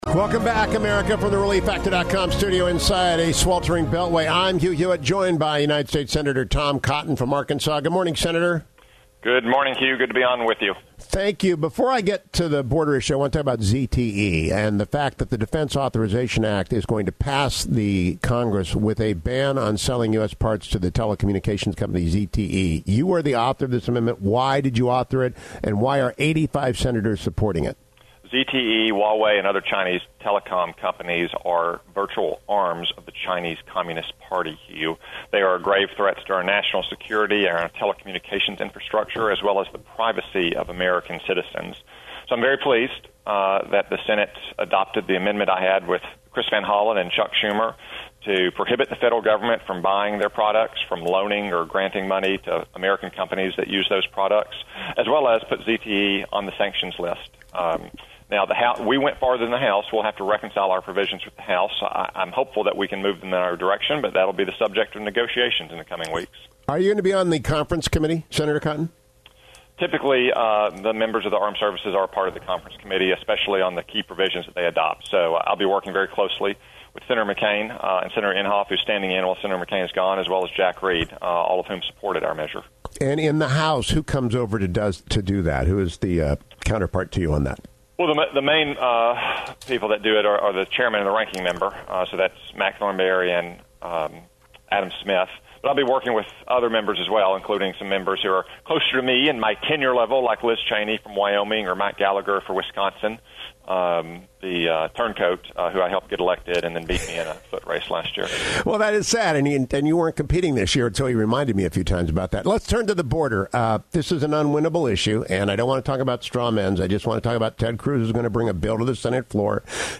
End of interview.